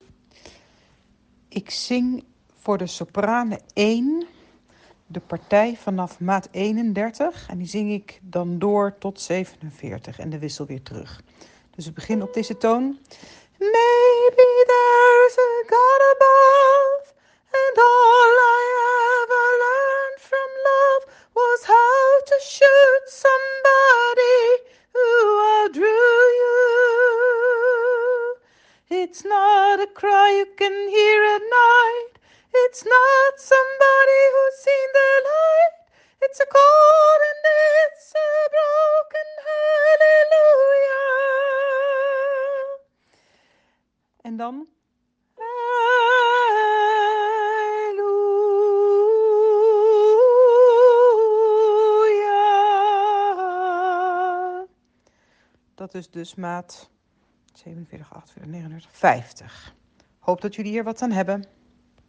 Tweede alt halleluja Eerste alt Halleluja Tweede sopraan halleluja Sopraan Halleluja